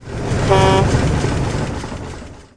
Rumbling Sound
轰隆轰隆